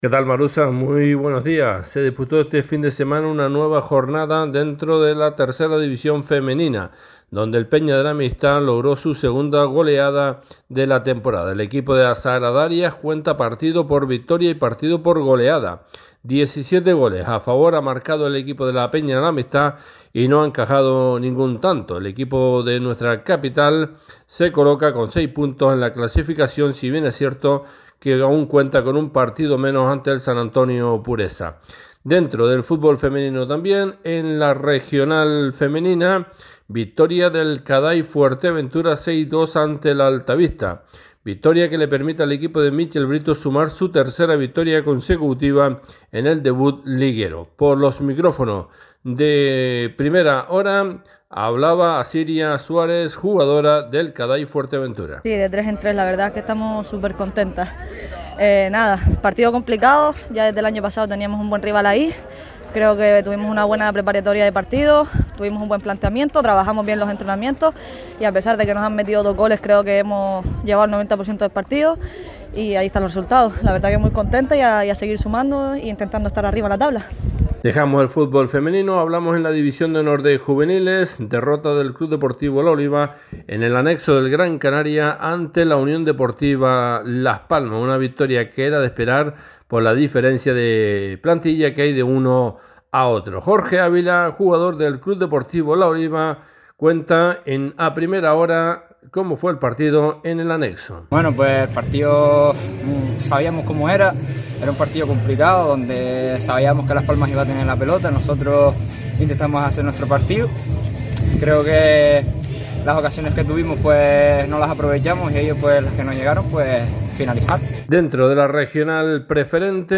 A Primera Hora, crónica deportiva